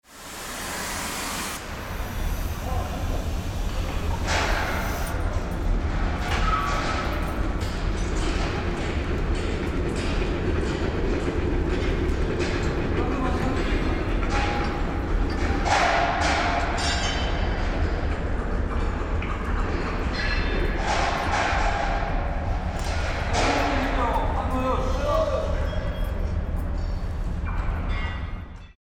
機械のモーター音やアナウンス、発車の合図......。地下鉄、都電荒川線、都営バスのそれぞれの場所でしか聞くことができない音を収録しました。
第12回地下鉄大江戸線「鉄の化学反応の音」
第12回 地下鉄大江戸線「鉄の化学反応の音」 レール交換をする保線作業時の音。